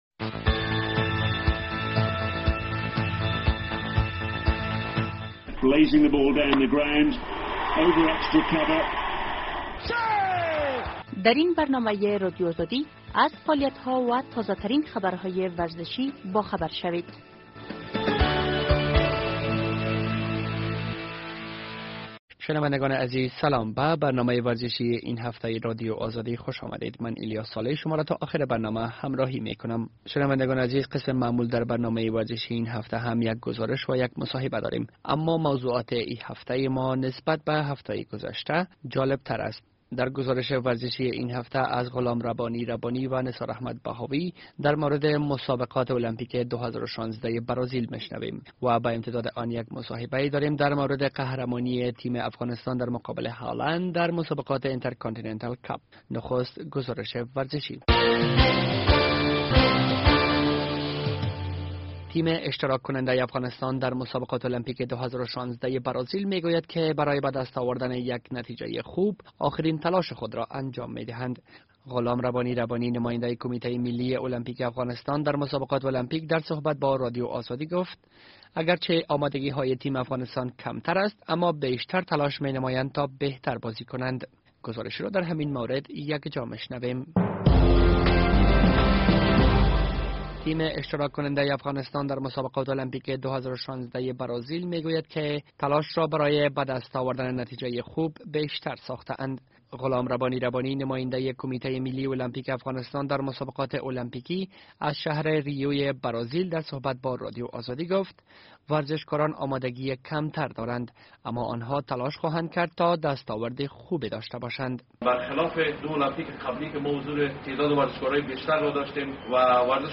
در این برنامۀ ورزشی یک گزارش در مورد اشتراک و تلاش ورشکاران افغان در مسابقات المپیکی و پیروزی آن‌ها را در کرکت ...